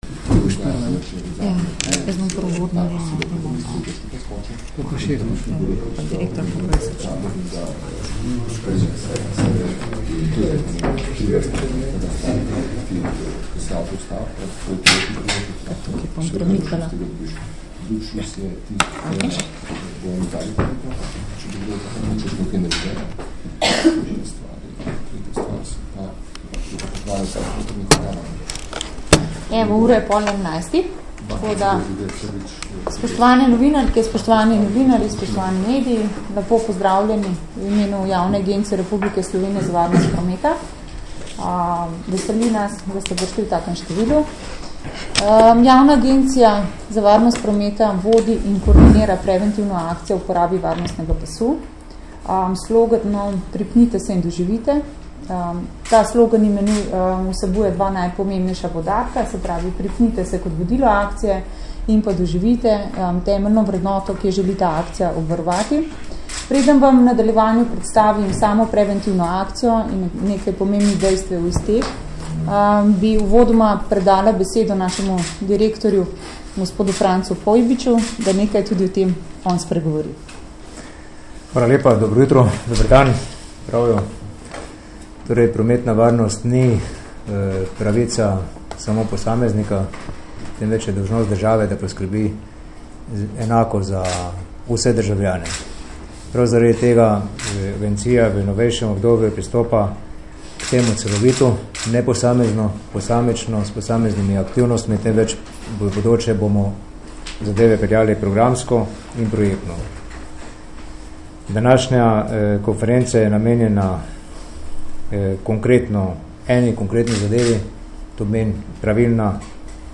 novinarska_varnostni_pas.MP3